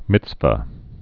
(mĭtsvə)